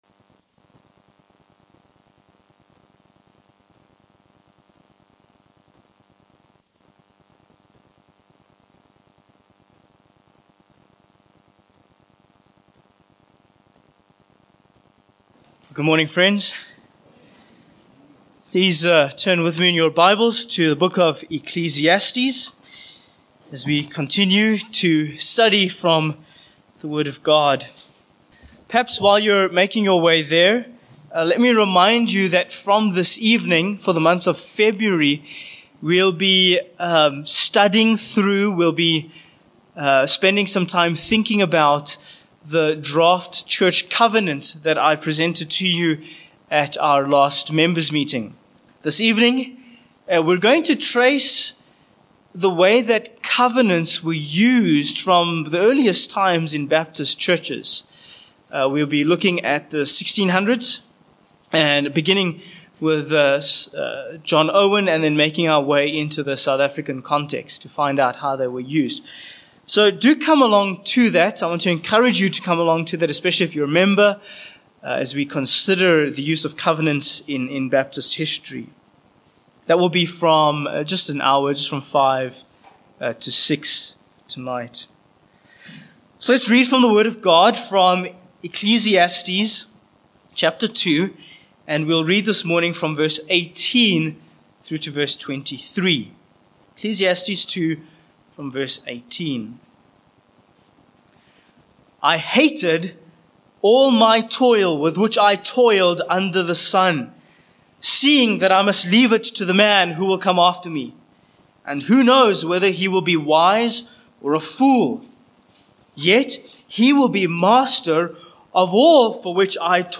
Ecclesiastes 2:18-23 Service Type: Morning Passage